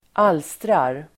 Uttal: [²'al:strar]